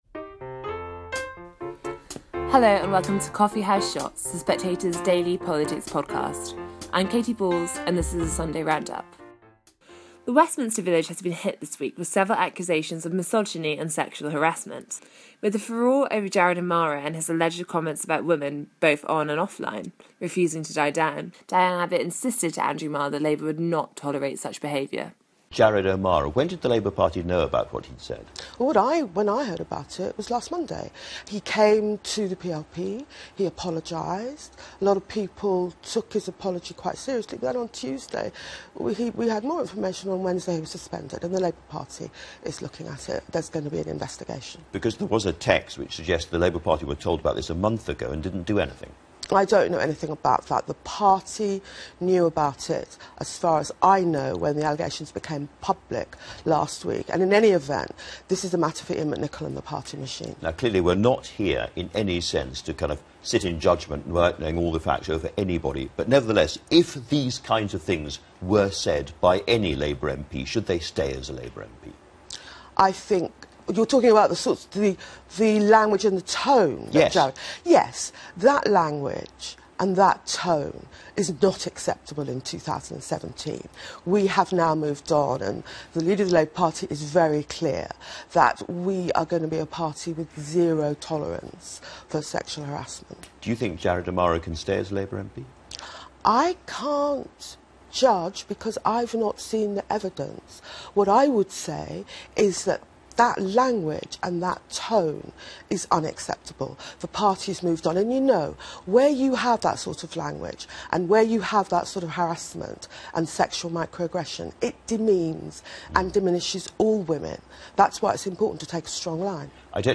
The Spectator's Sunday Roundup Coffee House Shots The Spectator News, Politics, Government, Daily News 4.4 • 2.1K Ratings 🗓 29 October 2017 ⏱ ? minutes 🔗 Recording | iTunes | RSS Summary Join Katy Balls as she takes you through the highlights of Sunday's political interviews. Interviewees this week include Diane Abbott, Jeremy Hunt, Lord Digby Jones and Barry Sheerman.